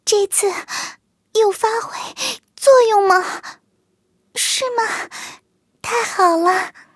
卡尔臼炮被击毁语音.OGG